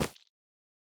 add_candle2.ogg